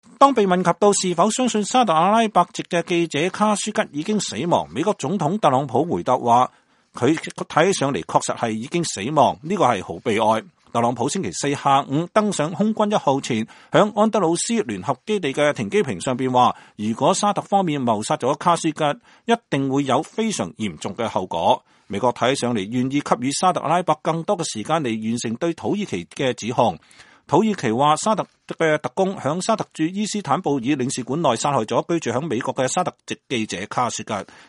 美國國務卿蓬佩奧在白宮與特朗普總統會面後與媒體談他的沙特阿拉伯之行。